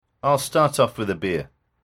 では、１のセンテンスと合わせて、イギリス英語の音声と一緒に解答を見てみましょう！